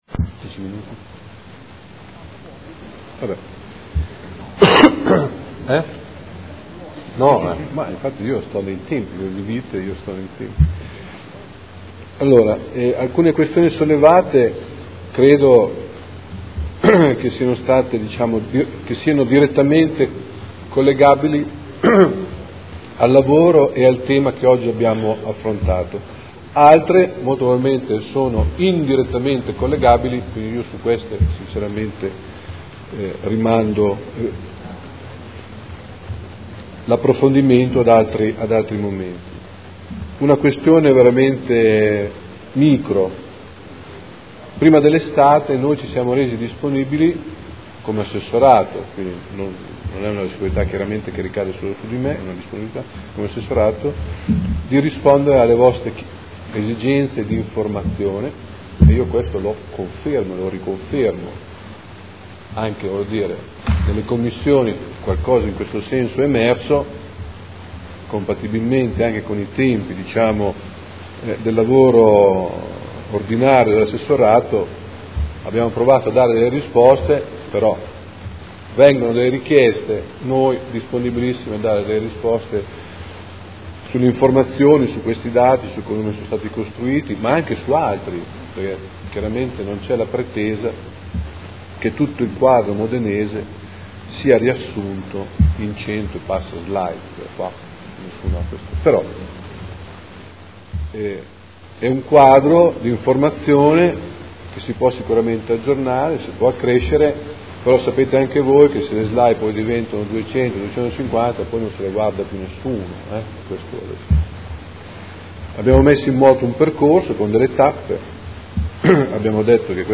Seduta del 17/09/2015. Conclude dibattito sul progetto "Educare Insieme", piano della buona scuola a Modena